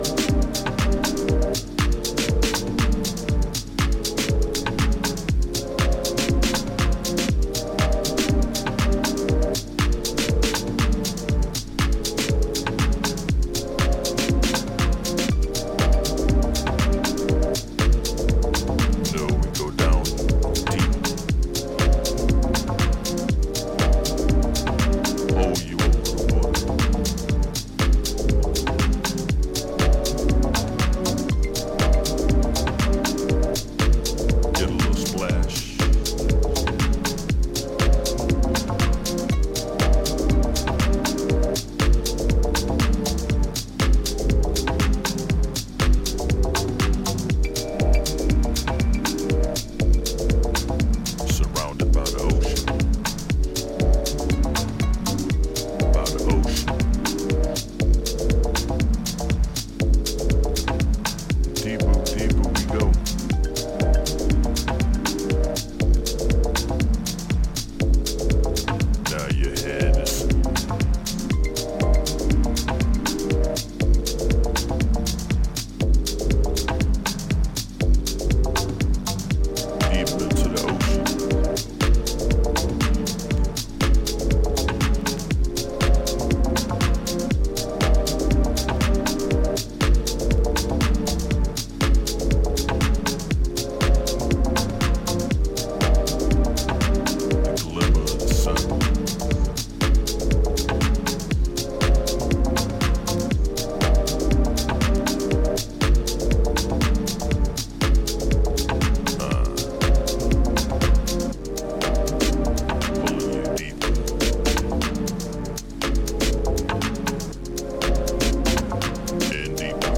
頭をもたげるようなレイトナイト感覚が充満した、彼らしい個性が光る1枚です！